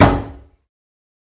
Стук кулаком по столу